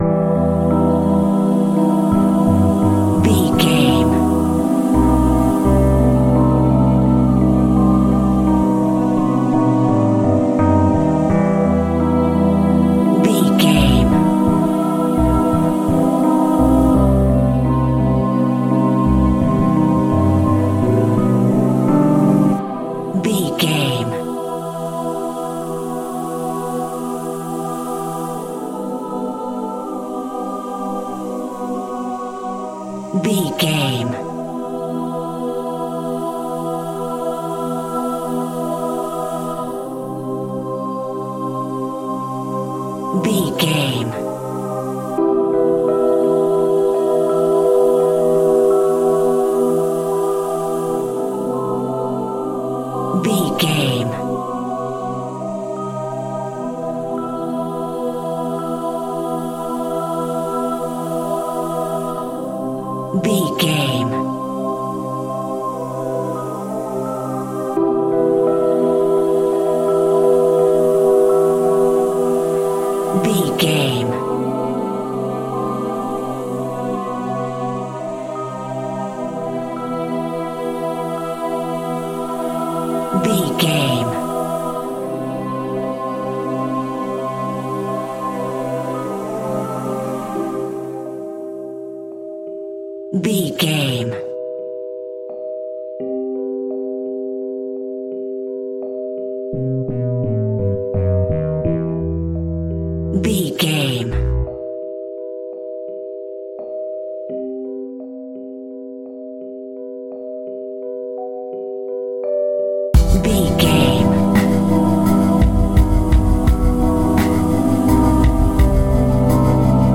In-crescendo
Aeolian/Minor
Slow
scary
tension
ominous
suspense
eerie
strings
synth
ambience
pads